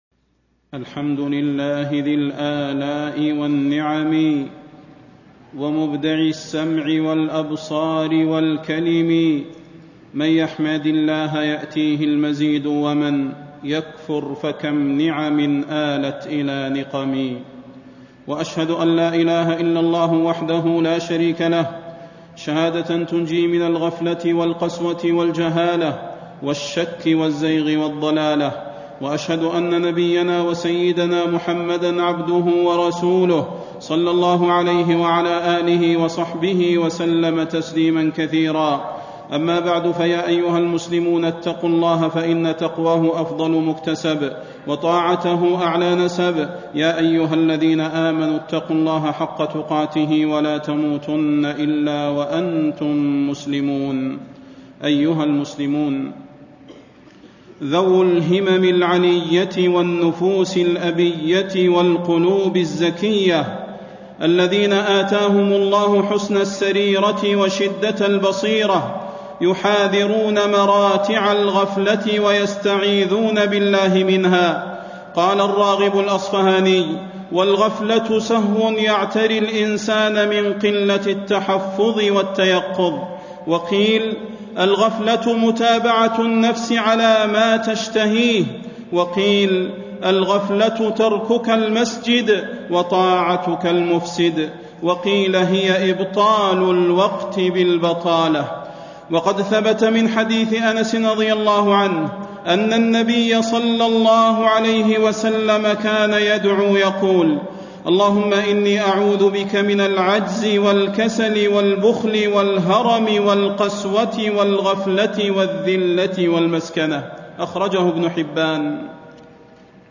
فضيلة الشيخ د. صلاح بن محمد البدير
تاريخ النشر ٨ شعبان ١٤٣٥ هـ المكان: المسجد النبوي الشيخ: فضيلة الشيخ د. صلاح بن محمد البدير فضيلة الشيخ د. صلاح بن محمد البدير التحذير من الغفلة